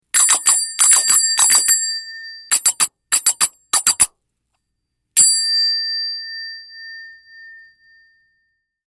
The largest finger cymbals in the collection.  These cymbals are made of premium quality brass, hand spun, burnished and aged for quality bell-like tone consistency.